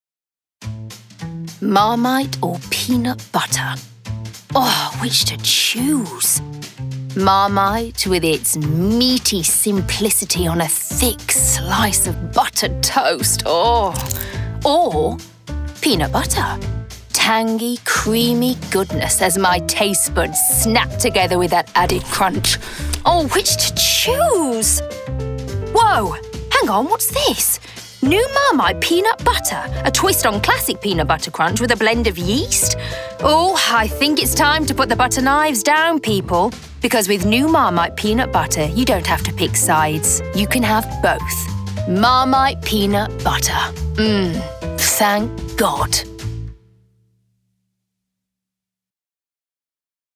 Voice Reel
Marmite Commercial - Dynamic, Expressive, Playful
Marmite - Dynamic, Expressive, Playful.mp3